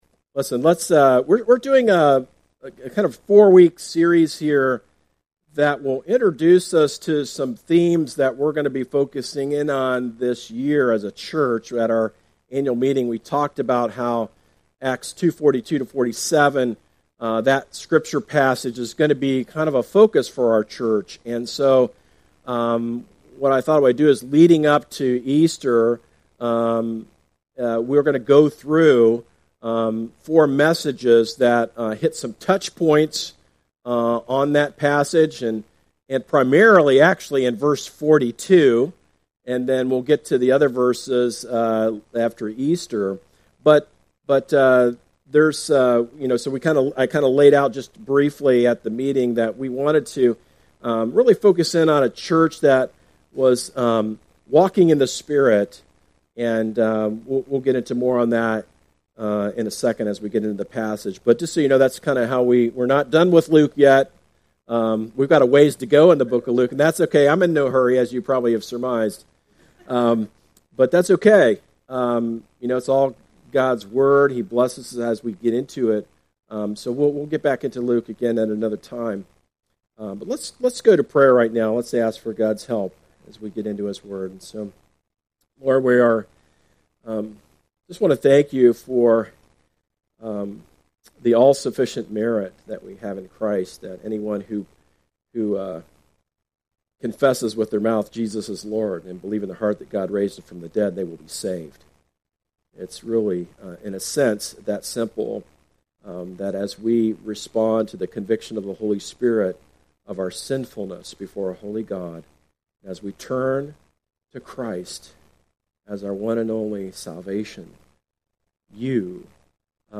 A message from the series "Finishing Well." Hebrews chapters 11 and 12 have much to say to us regarding living the Christian life.